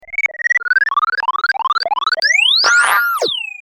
Download Free Cartoon Sound Effects
Download Cartoon sound effect for free.
Cartoon